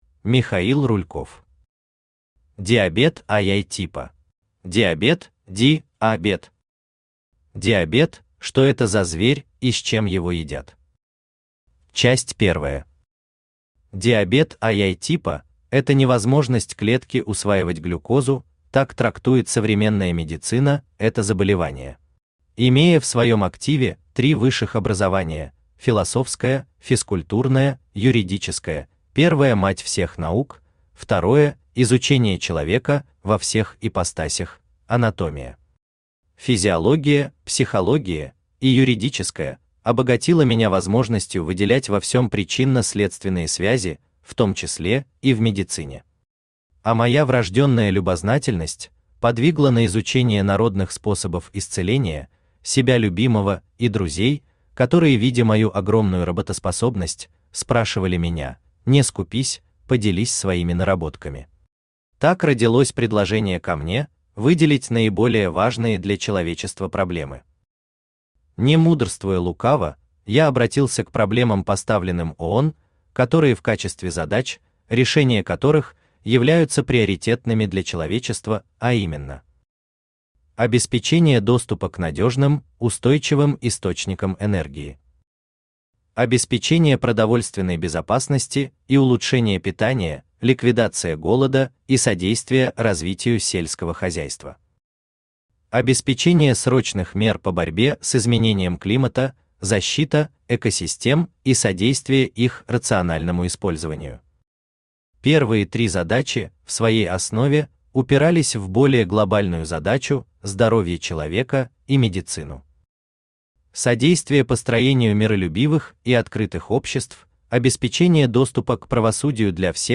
Аудиокнига Диабет | Библиотека аудиокниг
Aудиокнига Диабет Автор Михаил Михайлович Рульков Читает аудиокнигу Авточтец ЛитРес.